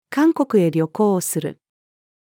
韓国へ旅行をする。-female.mp3